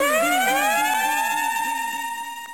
FLAWLESS swizz srien.wav